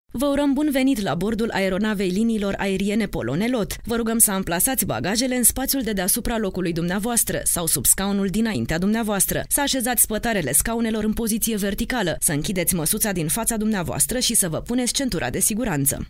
Sprecherin rumänisch für Werbung, TV, Industrie, Radio etc.
Sprechprobe: Werbung (Muttersprache):
Professional female voice over talent from Romania